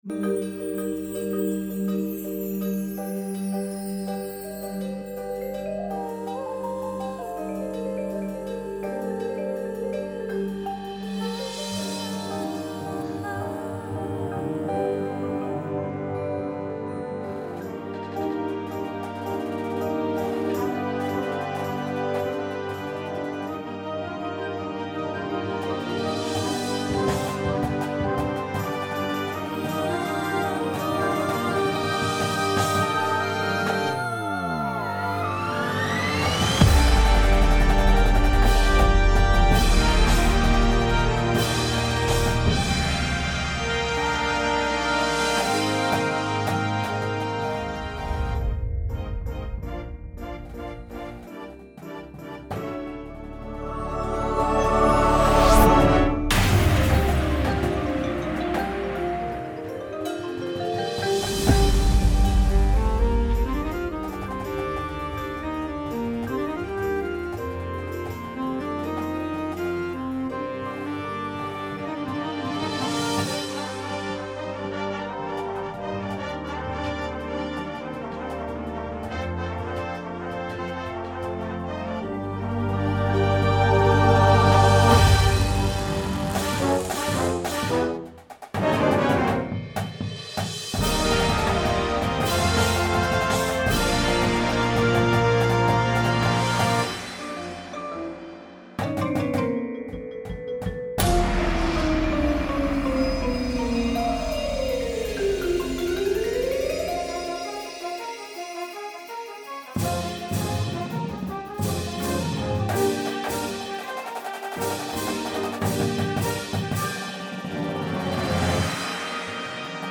There has been adjustments and cuts made to the show, so it will be slightly different than this sound file; however, most of it will be the same.